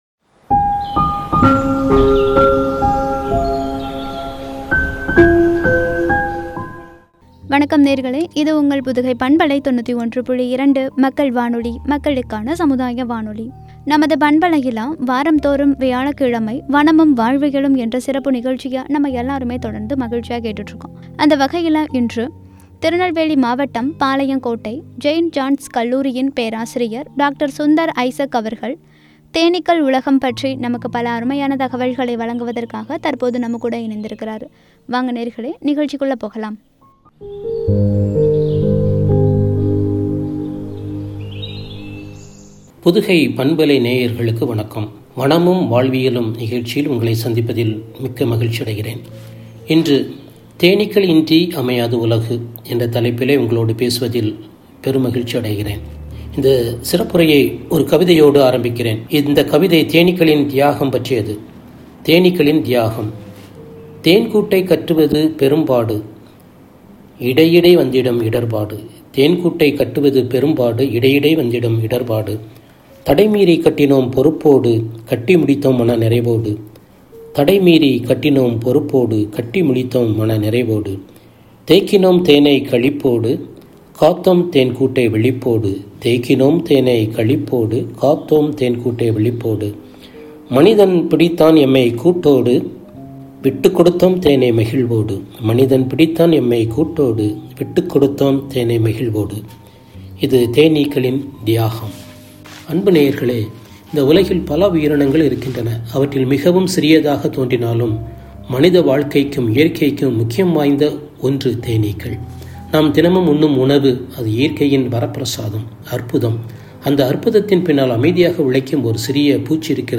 “தேனீக்கள் உலகம்” வனமும், வாழ்வியலும் (பகுதி- 145) என்ற தலைப்பில் வழங்கிய உரை.